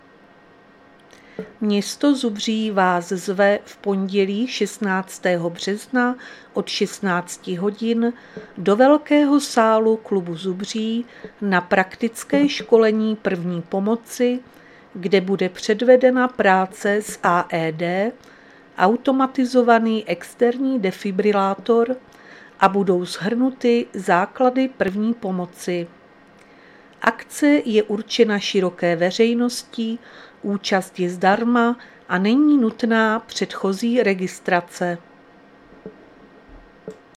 Záznam hlášení místního rozhlasu 10.3.2026